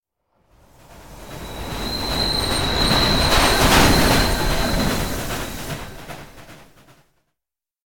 CBHQ_TRAIN_pass.ogg